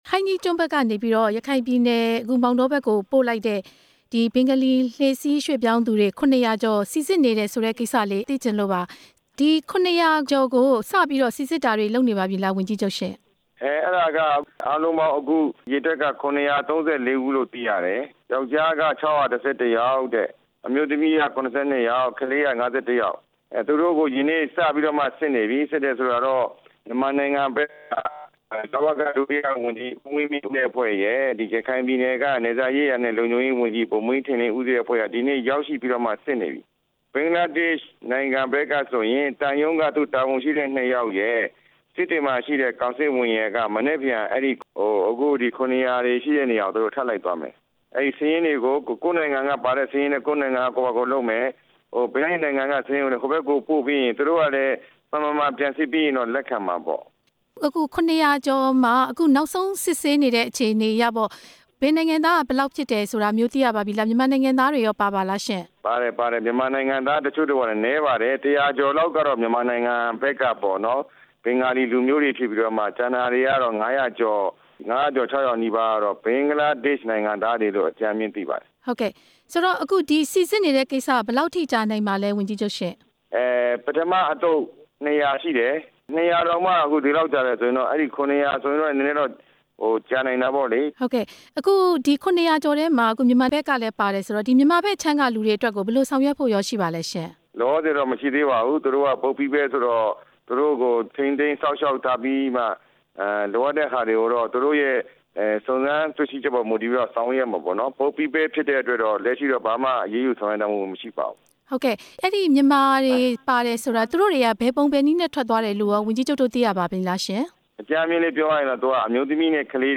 ဝန်ကြီးချုပ် ဦးမောင်မောင်အုန်းကို မေးမြန်းချက် နားထောင်ရန်